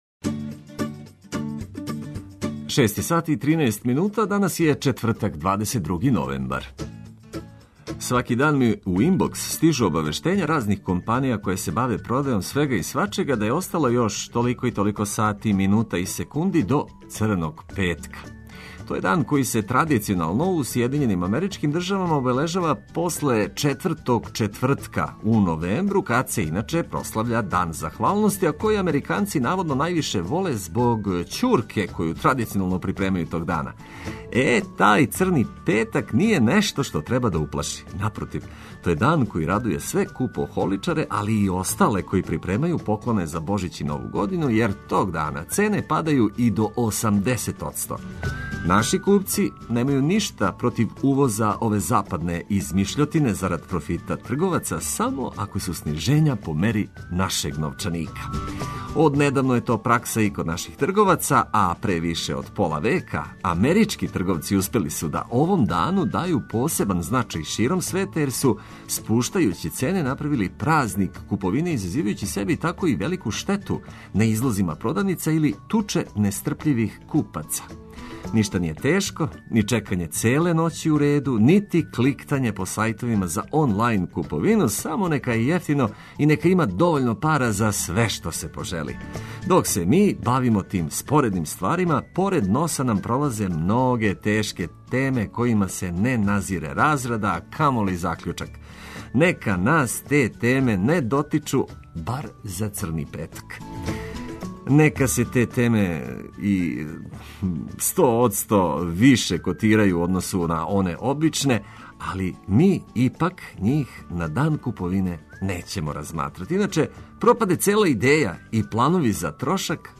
Буђење може бити узбудљиво и занимљиво ако је ваш будилник радио.